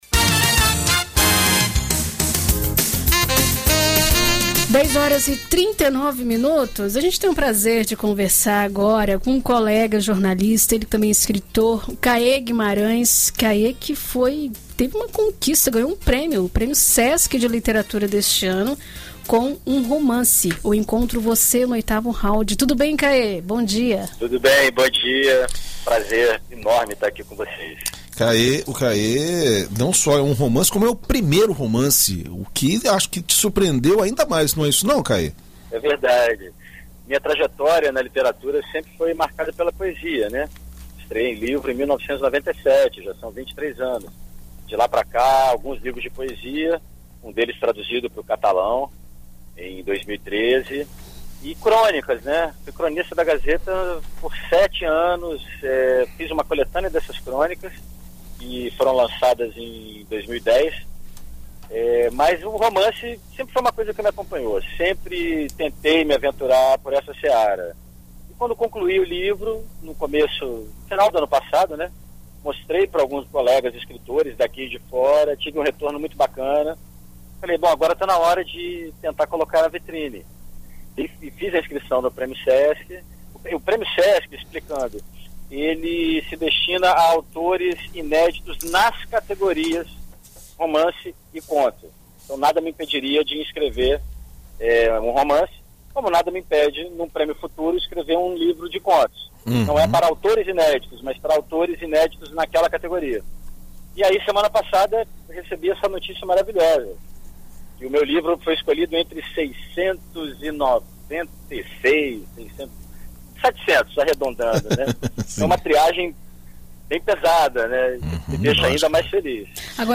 Entrevista: escritor capixaba conquista prêmio nacional